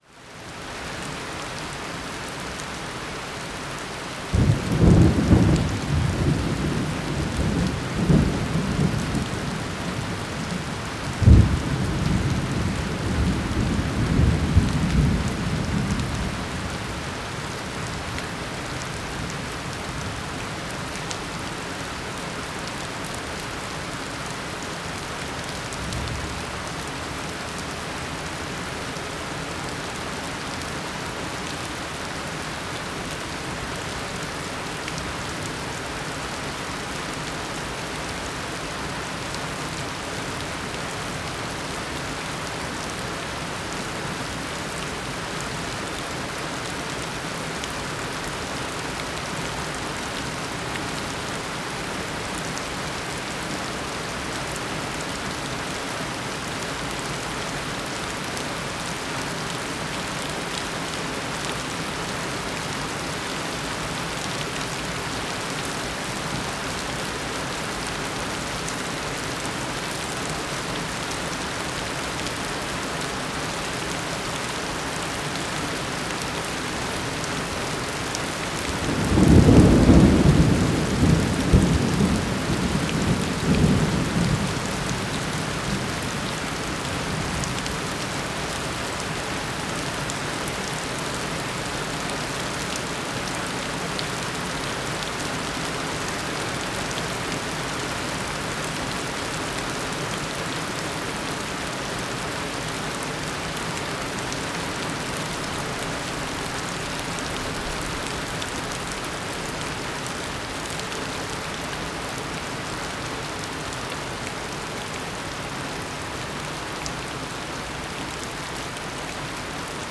rainandthunder1.mp3